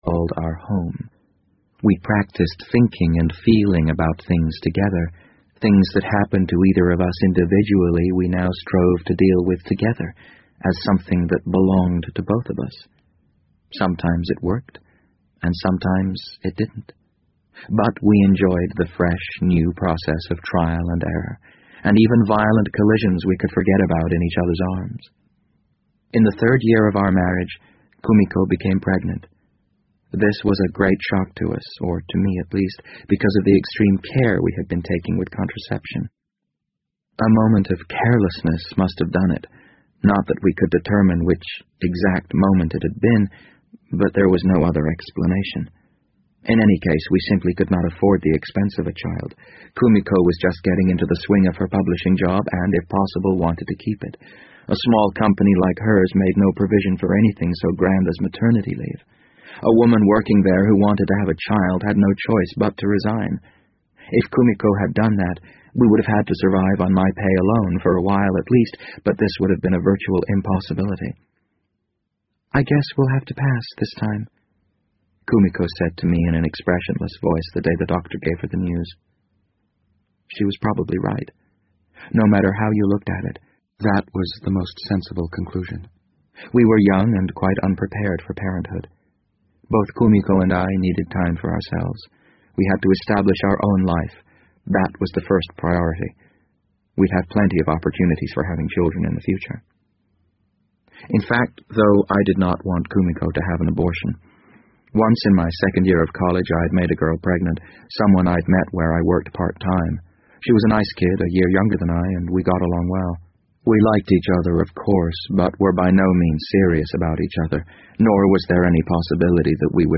BBC英文广播剧在线听 The Wind Up Bird 006 - 15 听力文件下载—在线英语听力室